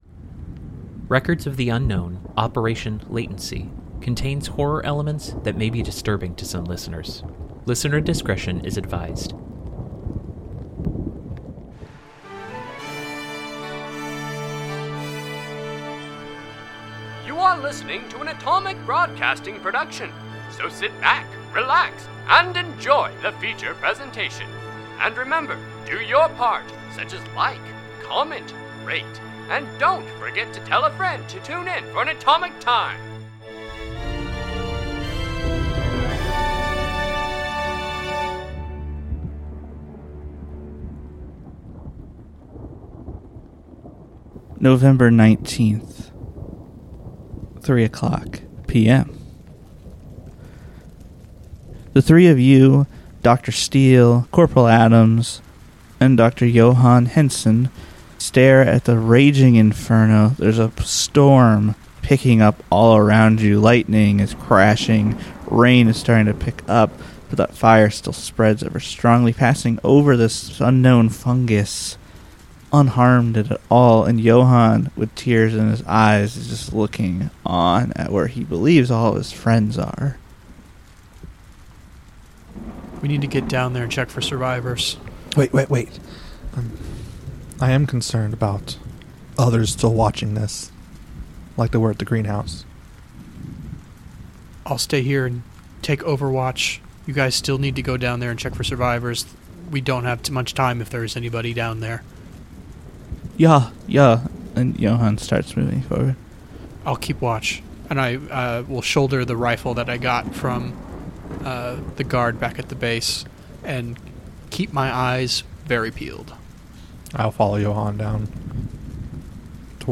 Records of the Unknown is an unscripted improvisational podcast